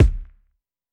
Havoc Kick 12.wav